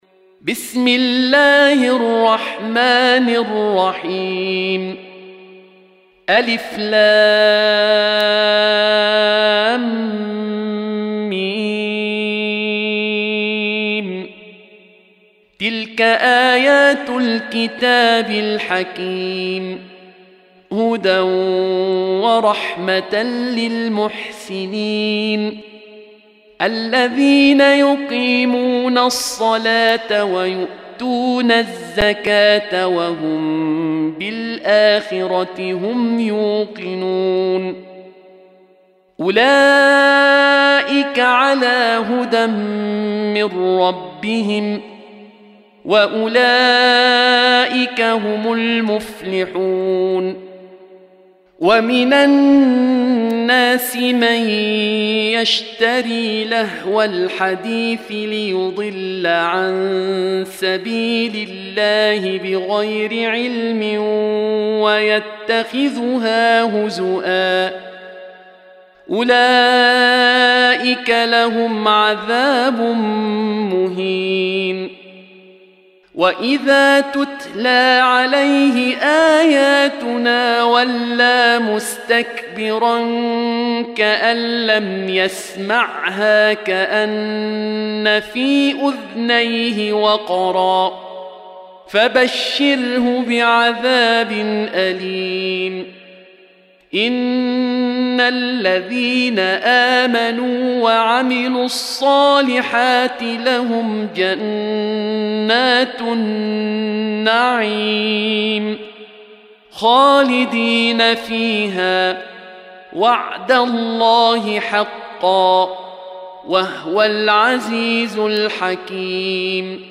Surah Sequence تتابع السورة Download Surah حمّل السورة Reciting Murattalah Audio for 31. Surah Luqm�n سورة لقمان N.B *Surah Includes Al-Basmalah Reciters Sequents تتابع التلاوات Reciters Repeats تكرار التلاوات